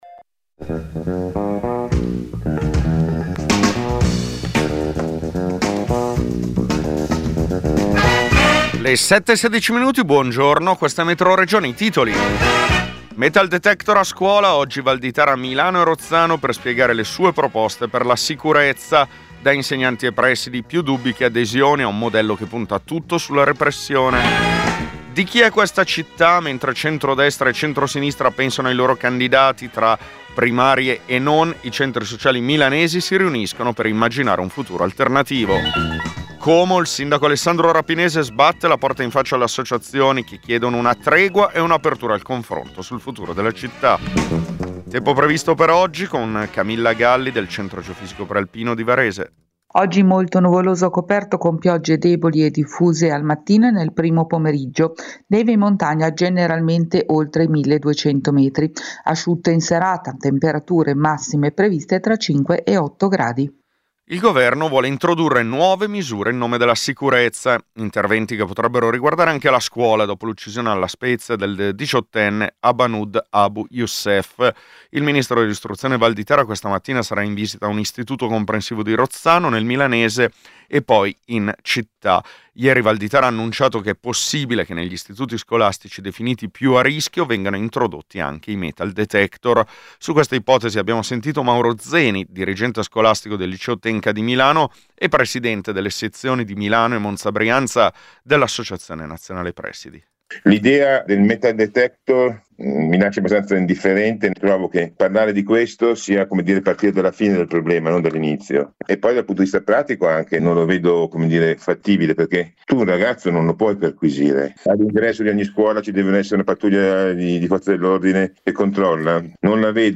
Metroregione è il notiziario regionale di Radio Popolare. Racconta le notizie che arrivano dal territorio della Lombardia, con particolare attenzione ai fatti che riguardano la politica locale, le lotte sindacali e le questioni che riguardano i nuovi cittadini.